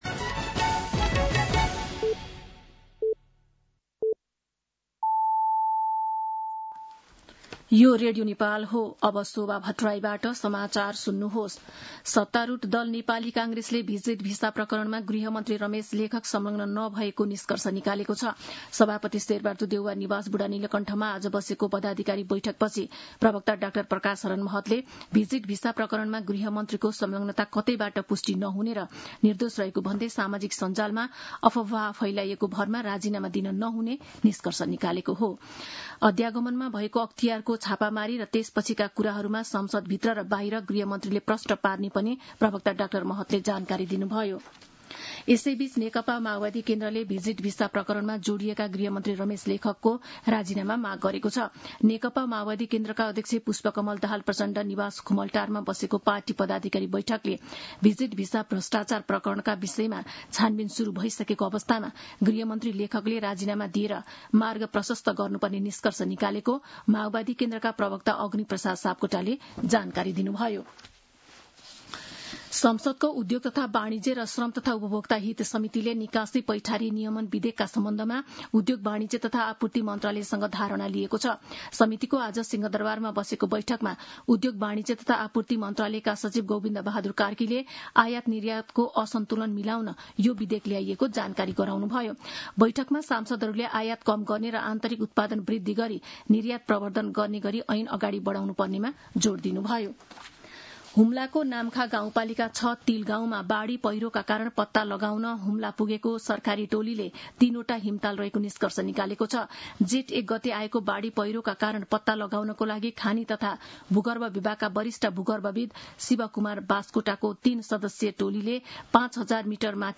दिउँसो ४ बजेको नेपाली समाचार : १२ जेठ , २०८२
4-pm-News-12.mp3